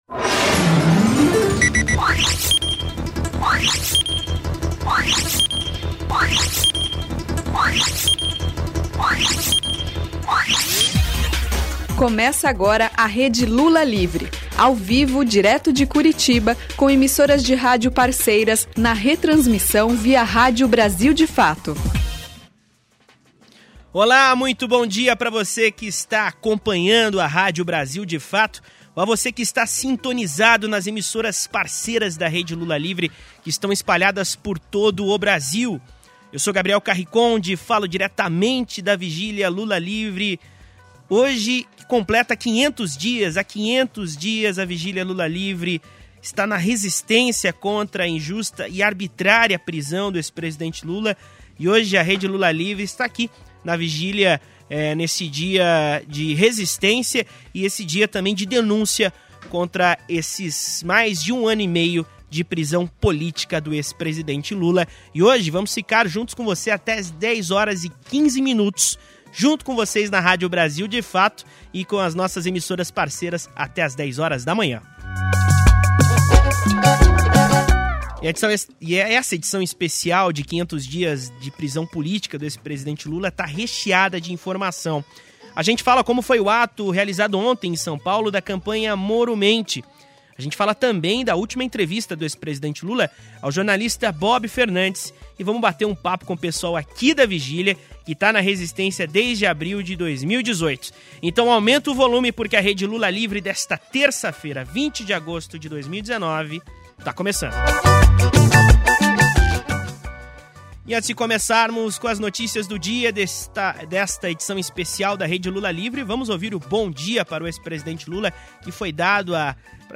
O programa desta terça-feira (20) apresentado diretamente da Vigília Lula Livre conversou com os […]